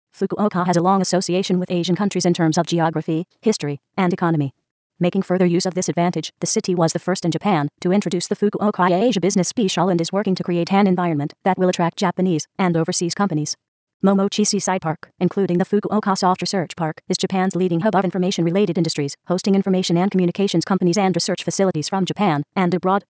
ネイティブ・スピーカーの最速（２００語/分：０．３秒/語）とは？
TextAloudのコンピュータ合成音で再現してみました。